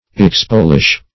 expolish - definition of expolish - synonyms, pronunciation, spelling from Free Dictionary
Search Result for " expolish" : The Collaborative International Dictionary of English v.0.48: Expolish \Ex*pol"ish\, v. t. [Cf. L. expolire.